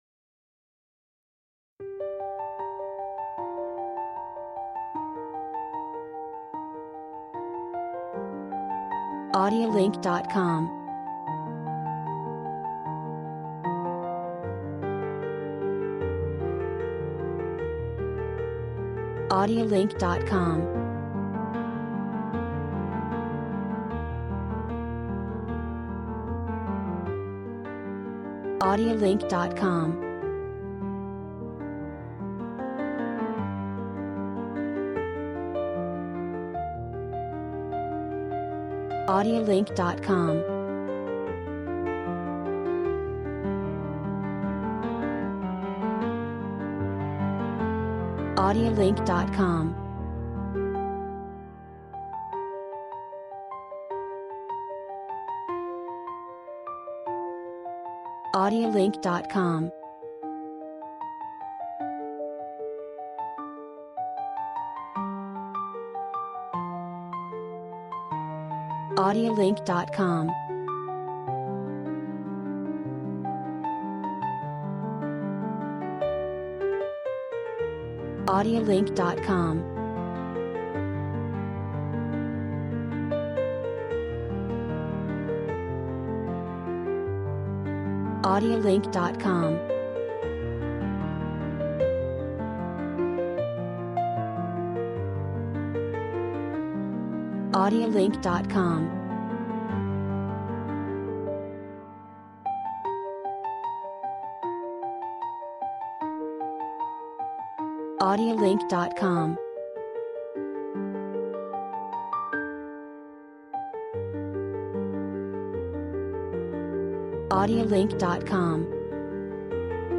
Sad Piano Background Music